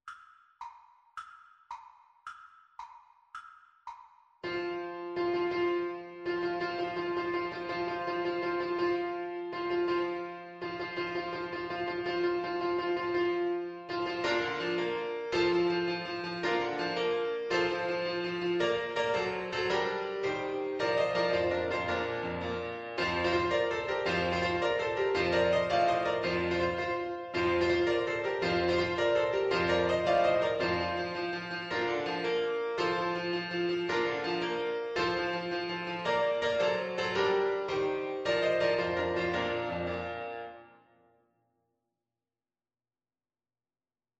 With energy .=c.110
6/8 (View more 6/8 Music)
Classical (View more Classical Trumpet Music)